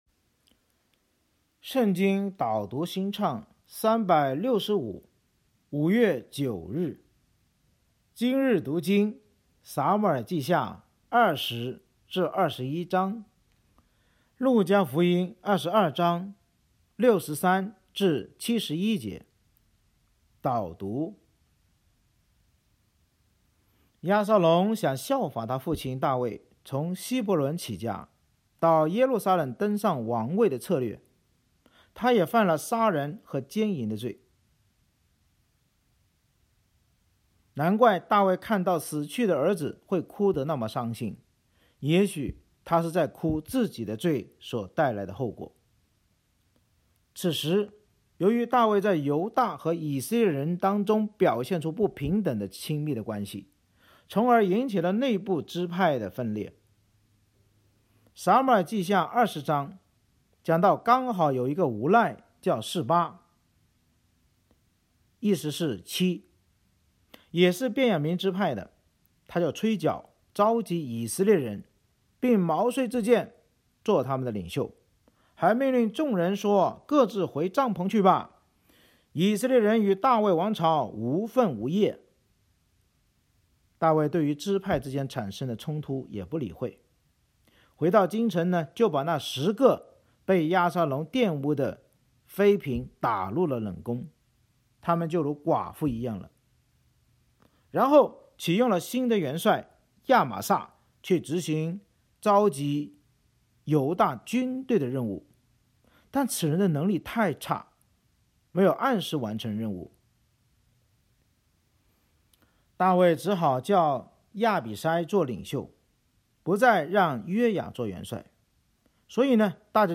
【导读新唱365】朗读5月9日.mp3